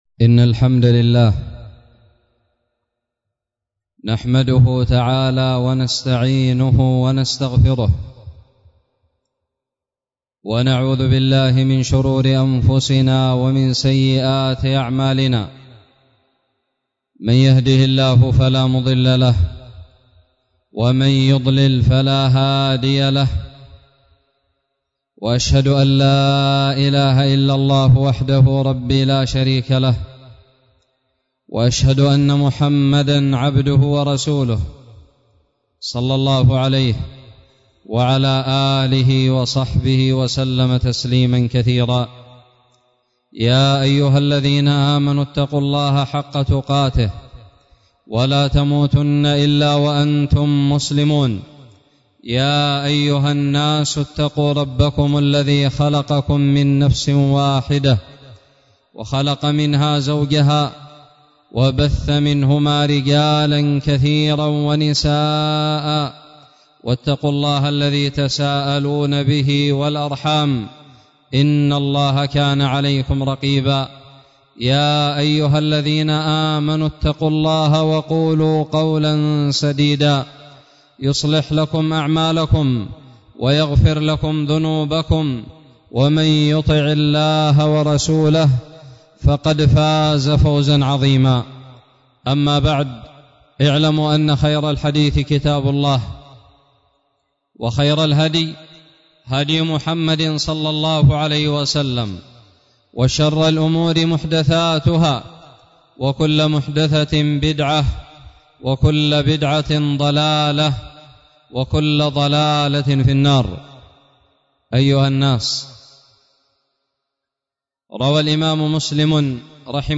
خطب الجمعة
ألقيت في لودر 7 رجب 1442هــ